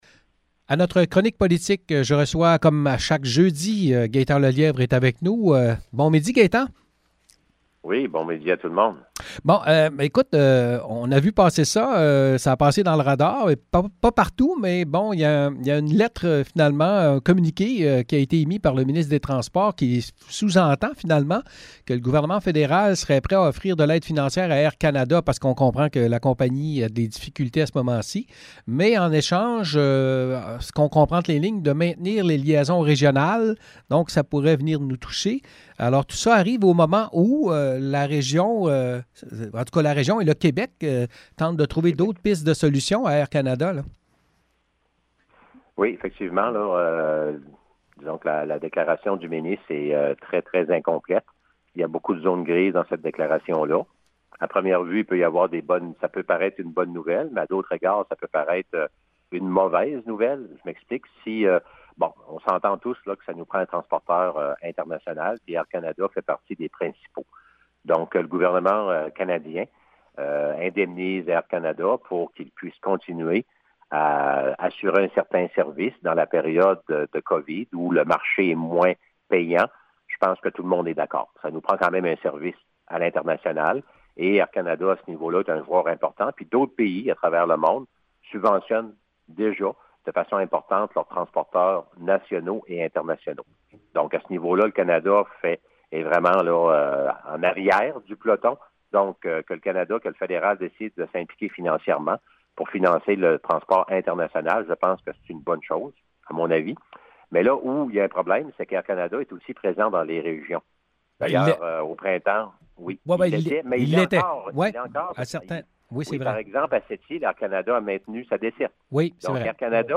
Chronique avec Gaétan Lelièvre: